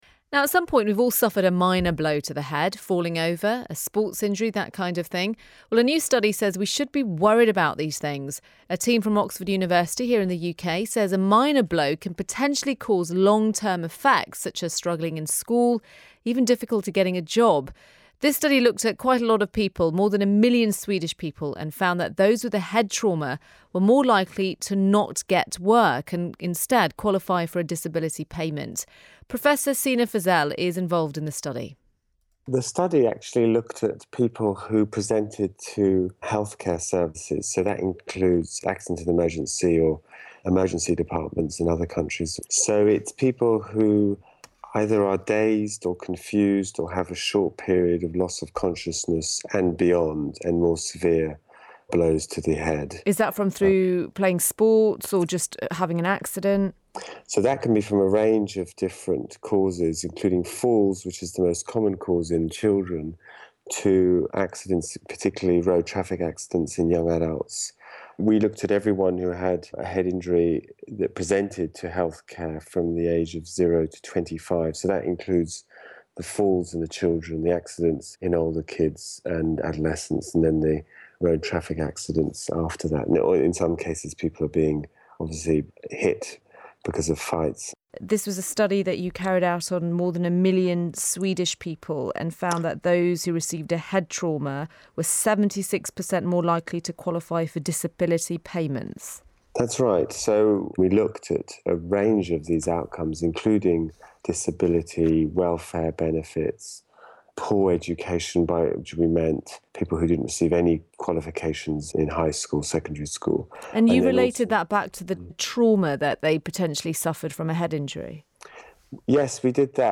Childhood traumatic brain injury interview